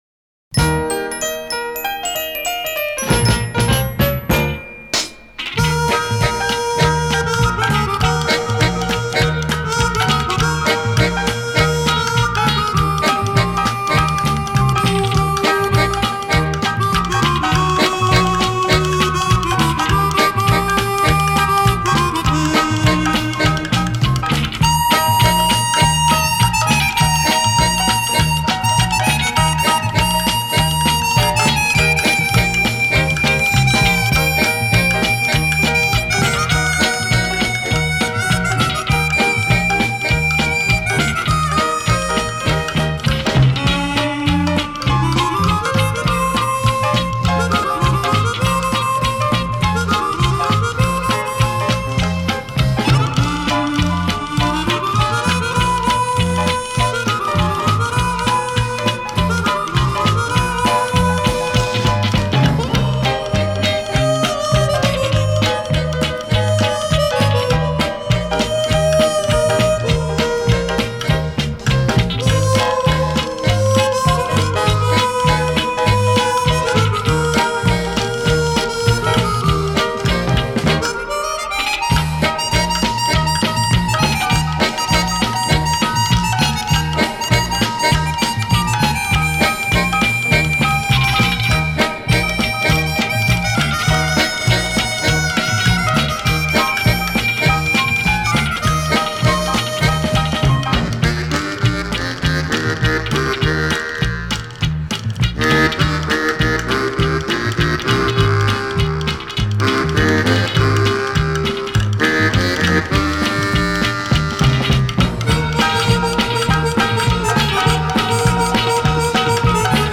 На этой записи с выделенной губной гармошкой и оркестром.